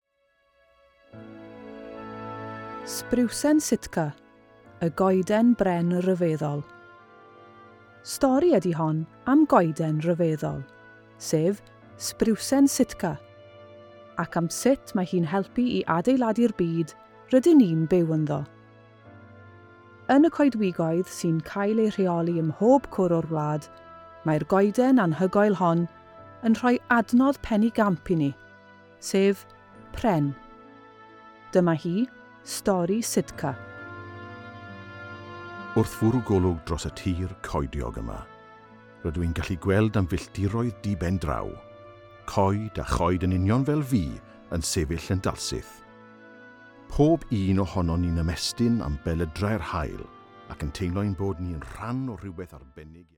Yn cael ei gyd-fynd â sgôr wreiddiol ac yn cael ei adrodd gan Fam Ddaear a Sitka ei hun, mae’r stori hon yn sicr o ddod â phleser a dysgu i bawb sy’n ei ddarllen.